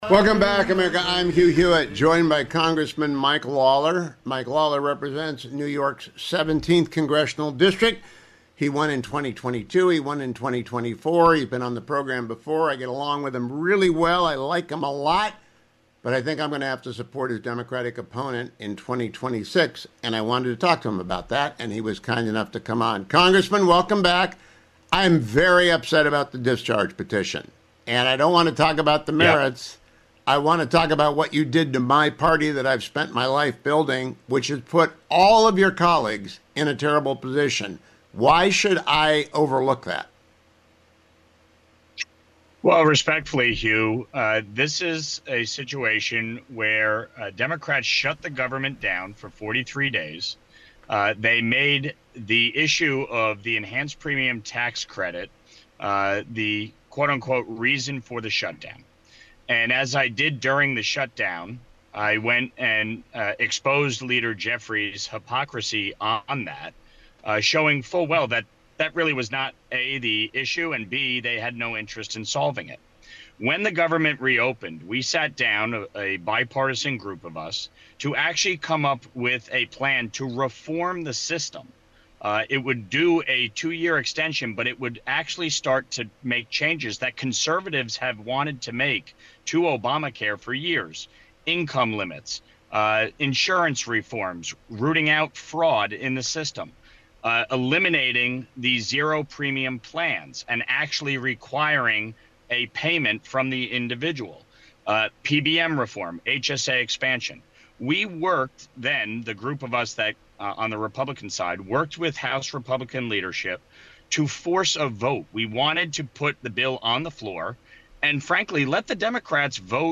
Congressman Mike Lawler joined me today to discuss why any Republican should support him in 2026 after he signed the discharge petition on a 3-year extension of Oamacare Covid-era subsidies: